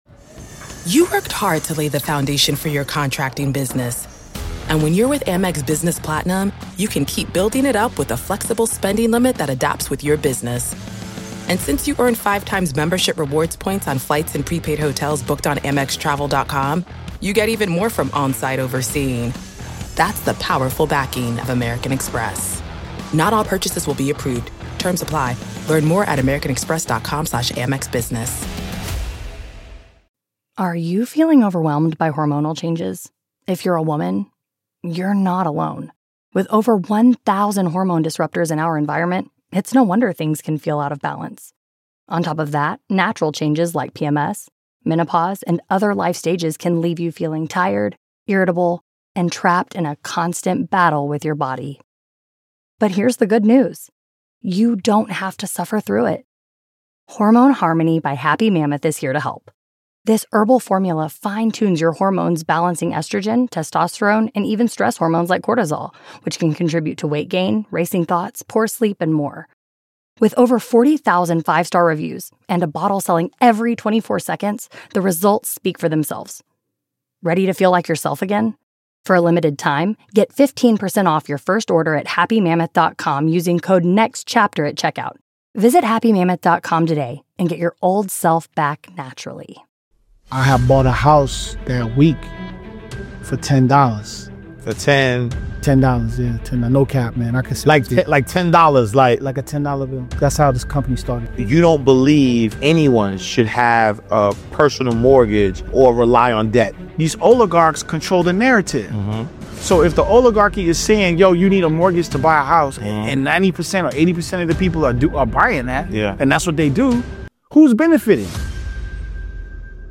Discover the power of tax liens, owning land, and building true generational wealth. This conversation will challenge everything you thought you knew about money, debt, and the American dream.